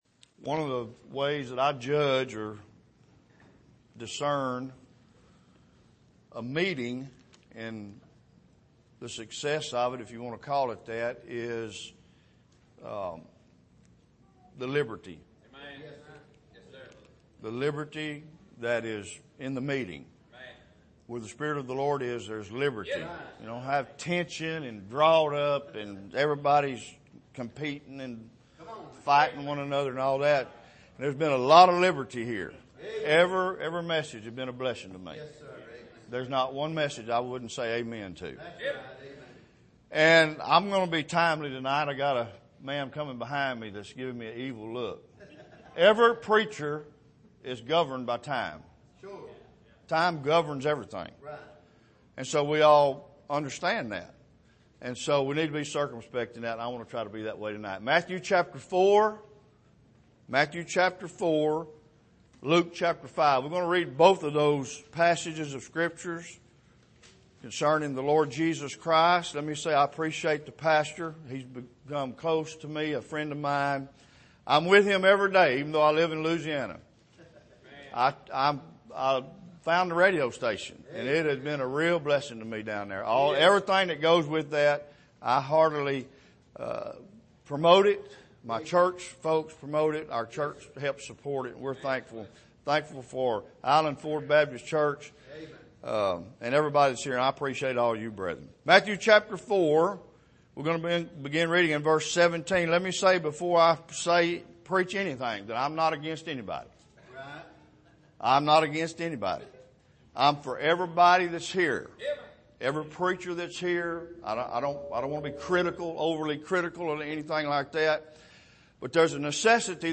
Here is an archive of messages preached at the Island Ford Baptist Church.
Haggai 1:1-7 Service: Missions Conference 2021 Missions Conference Being Identified With Christ In His Mission August 26